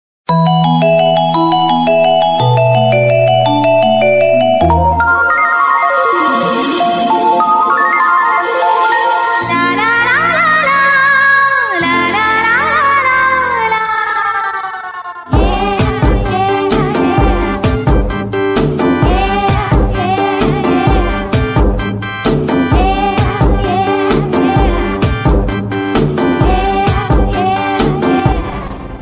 Главная » Рингтоны » Рингтоны из фильмов и мультфильмов